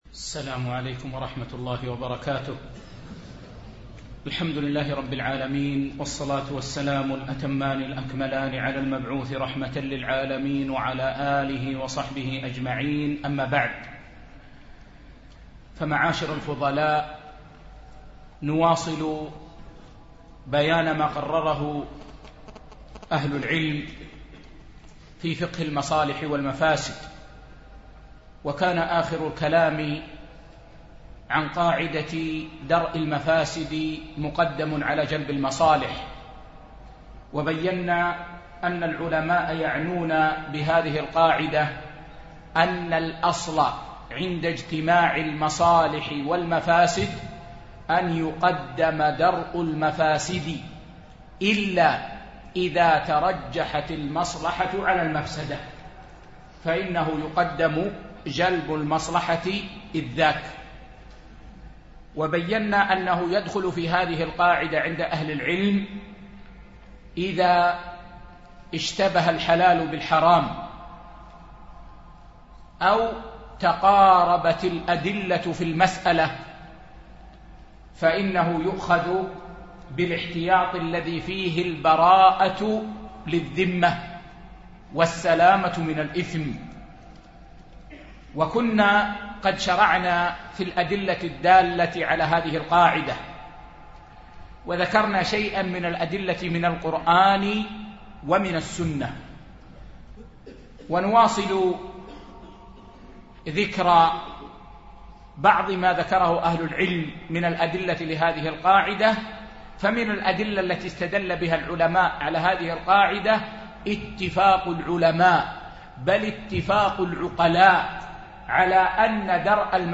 فقه المصالح والمفاسد ـ الدرس الثامن